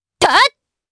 Chrisha-Vox_Attack3_jp.wav